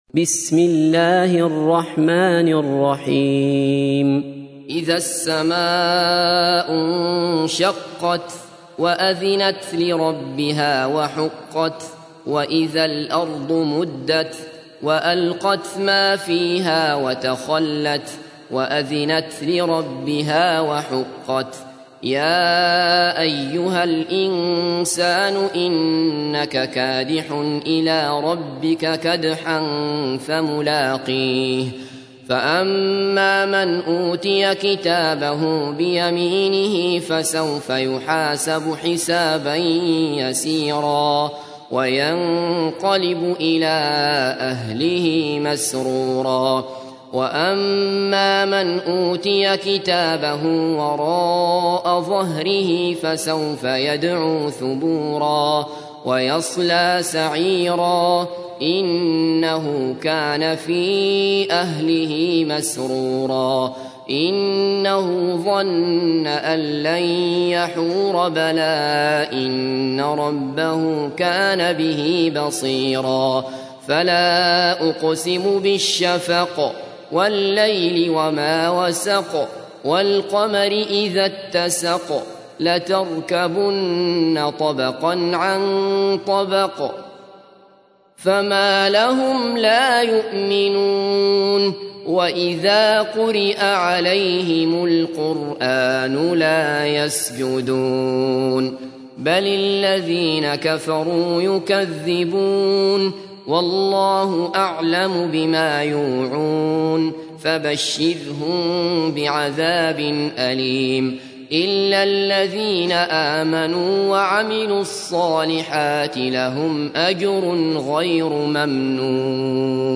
تحميل : 84. سورة الانشقاق / القارئ عبد الله بصفر / القرآن الكريم / موقع يا حسين